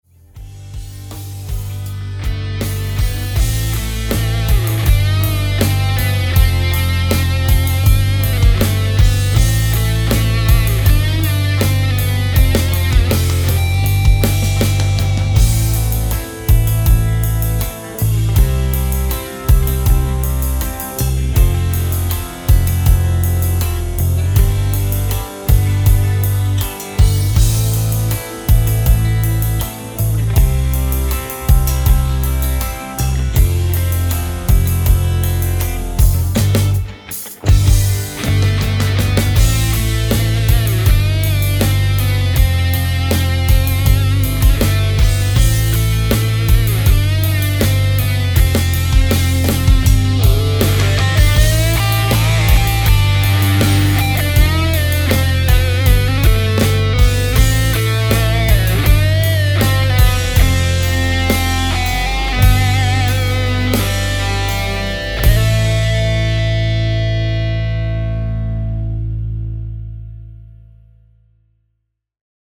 Всё, кроме барабасов ;) сделано через ПОД.